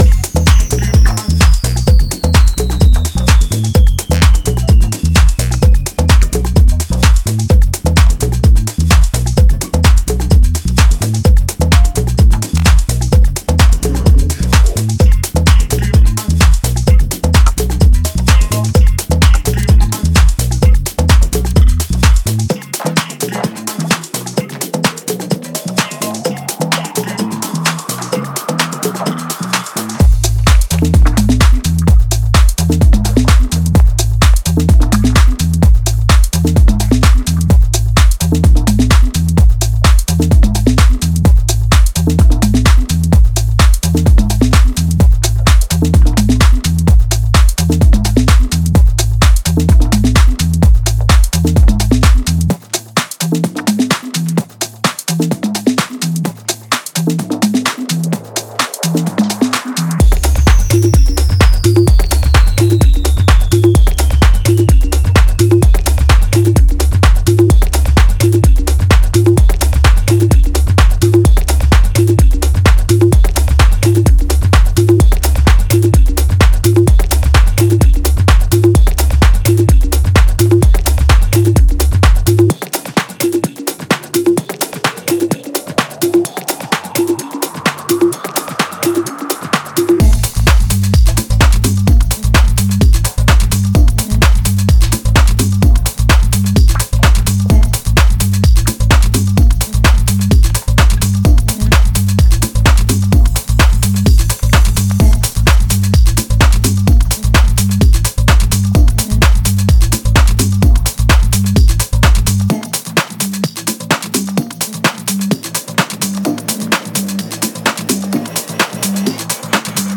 デモサウンドはコチラ↓
Genre:Tech House